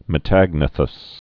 (mĭ-tăgnə-thəs)